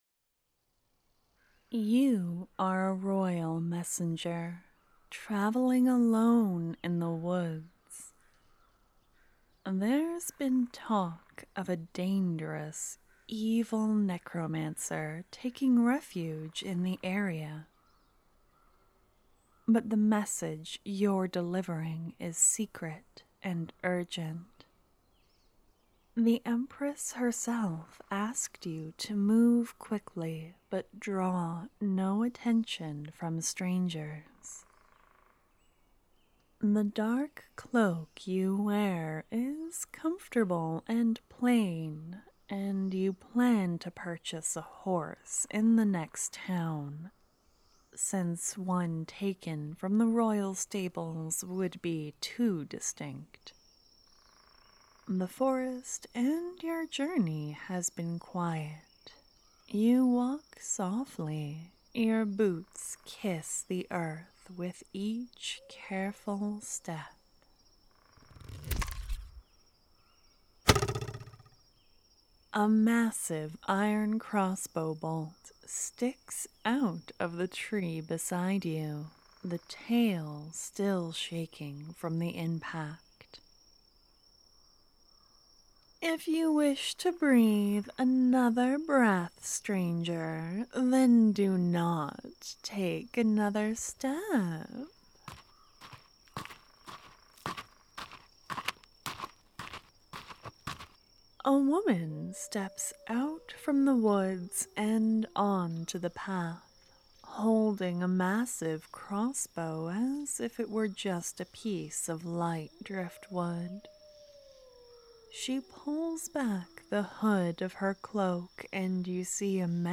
I had a lot of time to this week and I wanted to challenge myself, to see if I could make a character sound like she was moving around, searching for you. You guys deserve a really immersive experience~!So enjoy being hunted through the woods by a bounty hunter who thinks you are prey~ Take care of yourself today, I will see you tomorrow~!xoxo